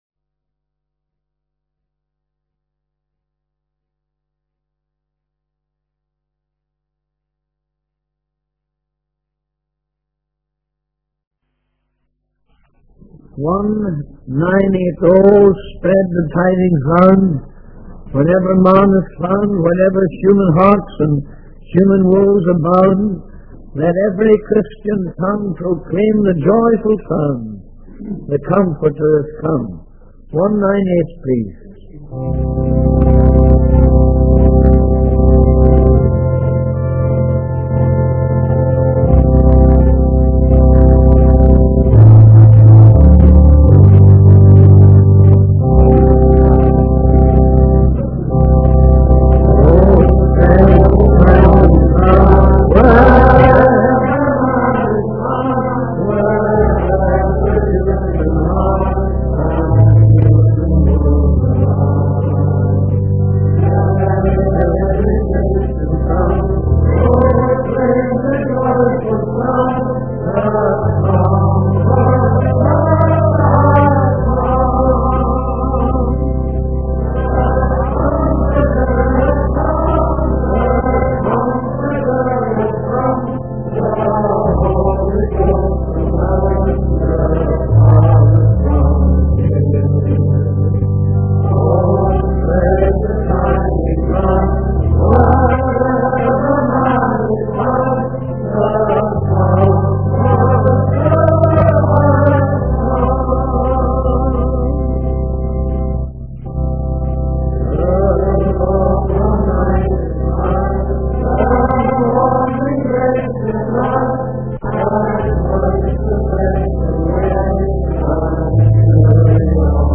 In this sermon, the preacher reflects on the chapter of the Bible and discusses various aspects of it.